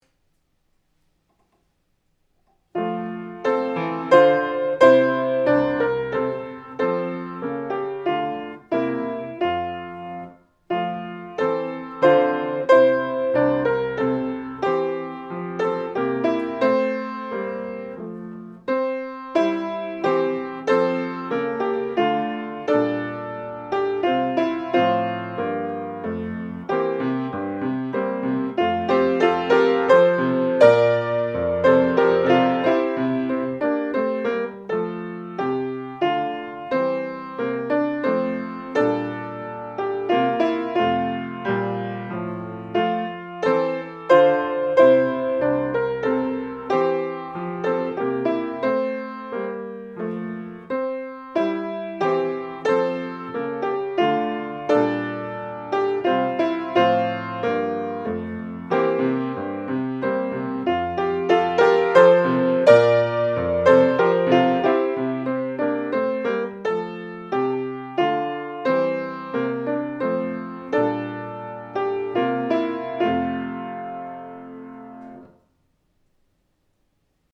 ⭐予選審査では、下記の課題曲の伴奏をご利用いただくことも可能です。
冬景色前奏４小節